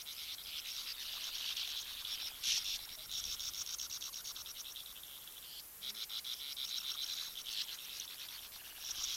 Bank Swallow